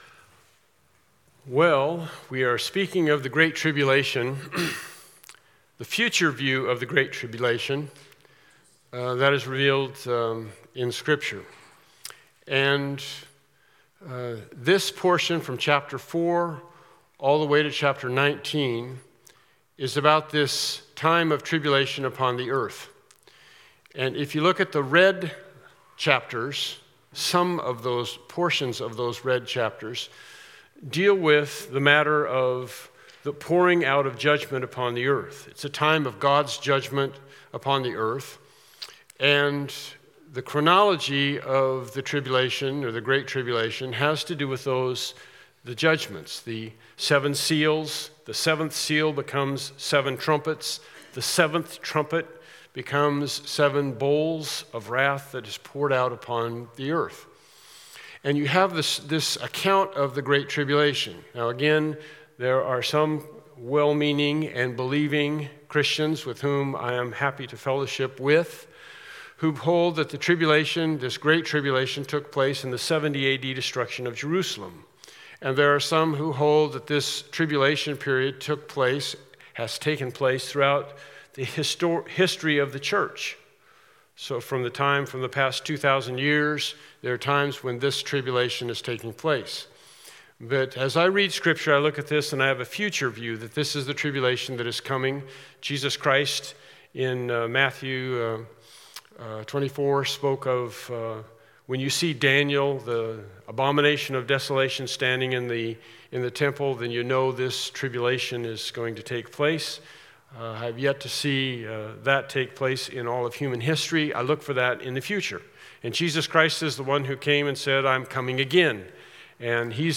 Revelation 7 Service Type: Evening Worship Service « “Hidden With Christ” “Put Off